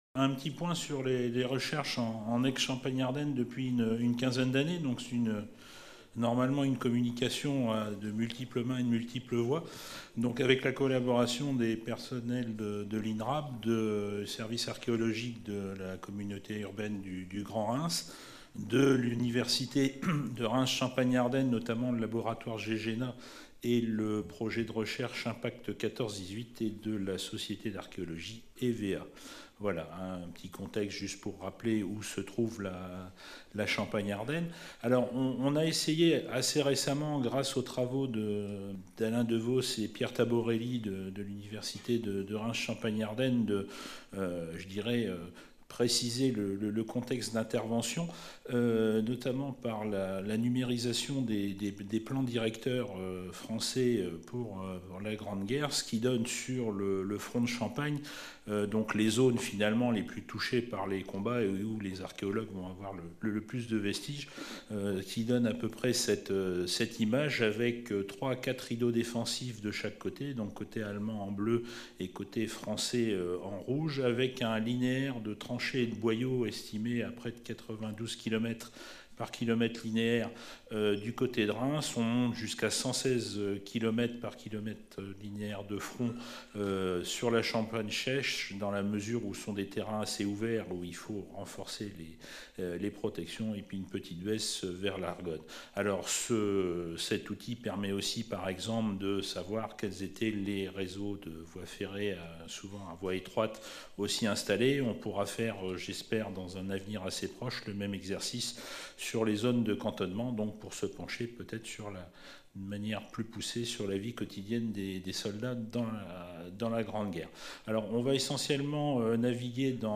Cette communication a été filmée lors du colloque international intitulé De Verdun à Caen - L’archéologie des conflits contemporains : méthodes, apports, enjeux qui s’est déroulé au Mémorial de Caen les 27 et 28 mars 2019, organisé par la DRAC Normandie, la DRAC Grand-Est, l’Inrap et l’Université de Caen (MRSH-HisTeMé) avec le partenariat de la Région Normandie, du Département du Calvados, de la Ville de Caen et du Groupe de recherches archéologiques du Cotentin.